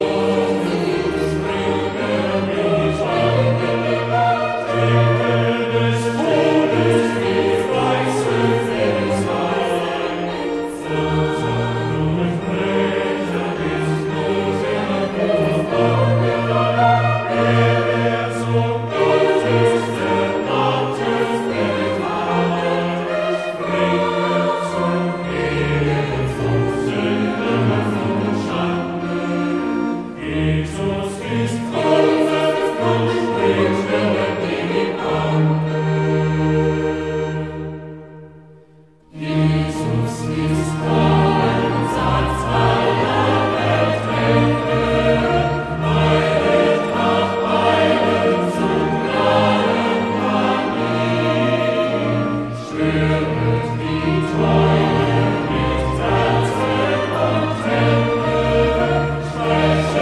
Choräle & Heilslieder